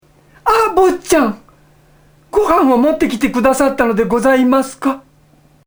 楳図かずおが、己の作品のワンシーンに自らの声で命を吹き込むという新企画「UMEZZ VOICE!」第二弾がついに公開！！
今回はその囚われの関谷に食料を届けにきた少年へ、関谷がロッカーの中から「あっ、ぼっちゃん！！」と声をかけるシーンを、楳図かずお自らが再現しました！